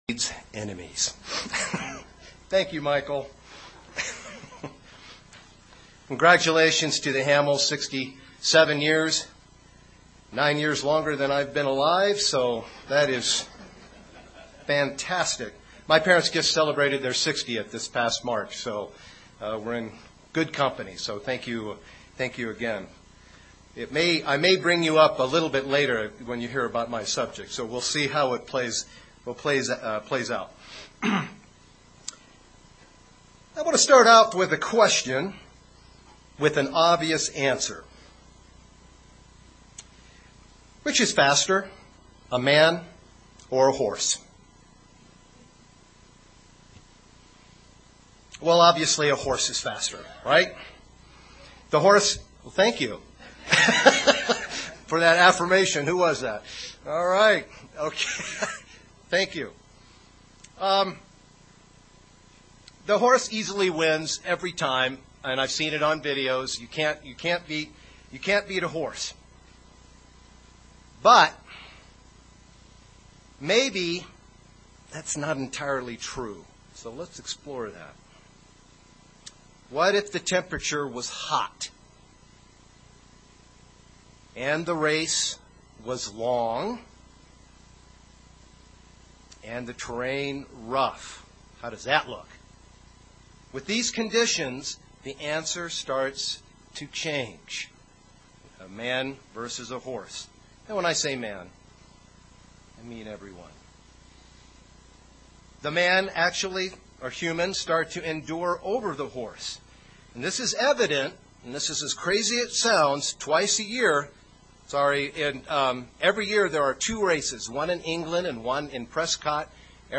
Paul, in 2 Timothy, spoke about the preverbial race we run as Christians, and completing that race is staying faithful until death. This sermon focuses on the necessity of endurance and having the undying will to continue and persevere.